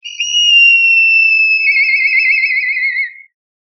鳶（トビ・トンビ）の鳴き声 着信音
鳴き声は「ピーヒョロロロロ…」と聞いたことがある鳴き声です。